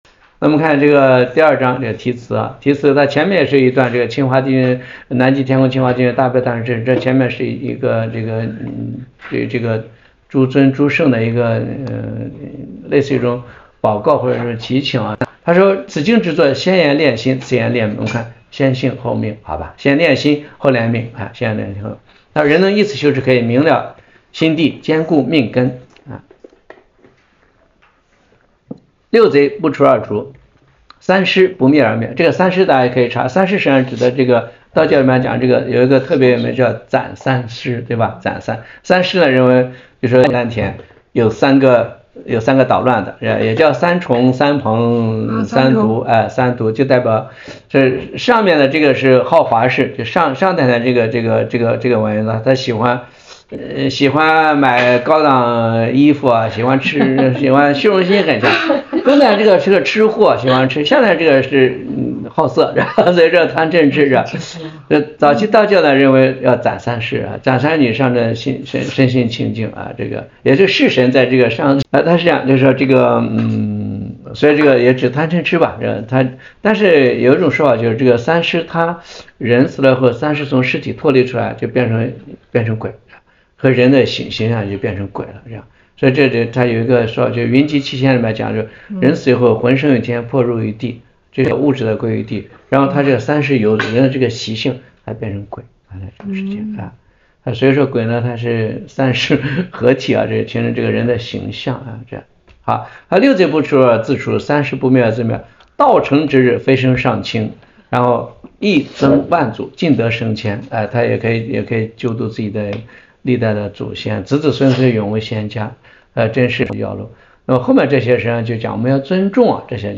由于录音设备故障 偶有语句缺失